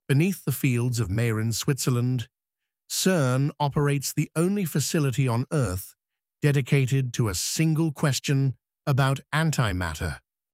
Audio Briefing
AI-narrated intelligence briefings for executives on the move.